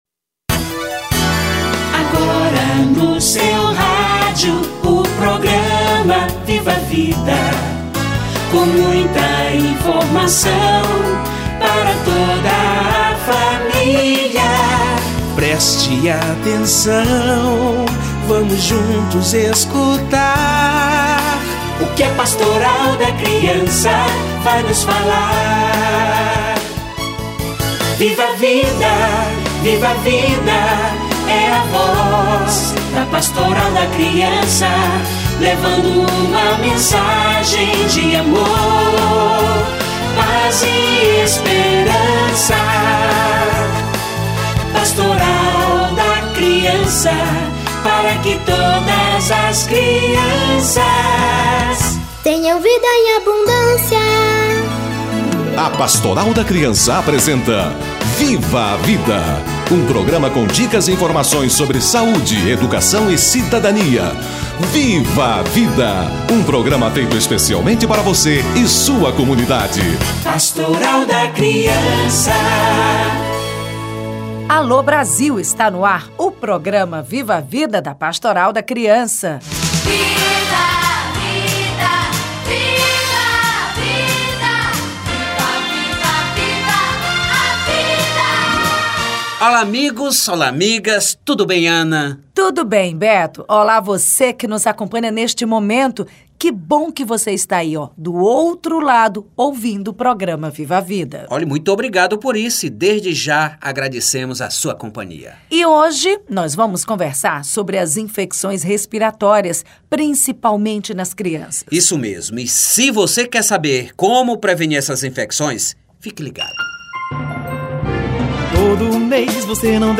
Infecções respiratórias - Entrevista